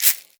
Index of /musicradar/essential-drumkit-samples/Shaken Perc Kit
Shaken Caxixi .wav